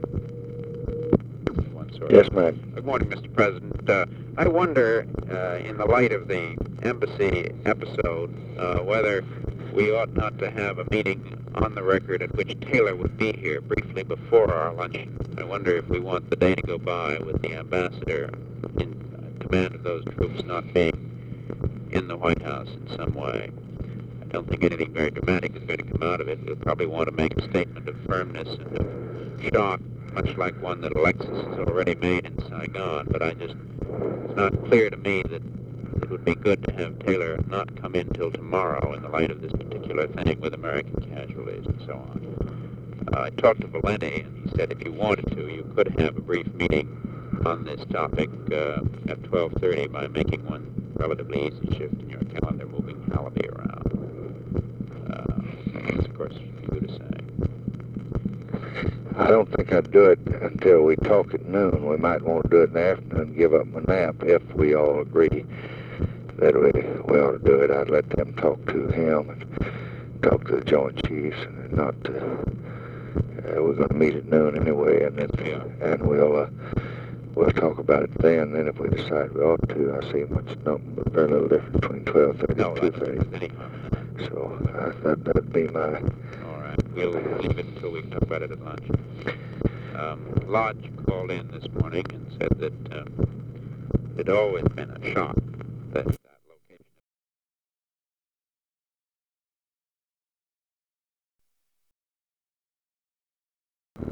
Conversation with MCGEORGE BUNDY, March 30, 1965
Secret White House Tapes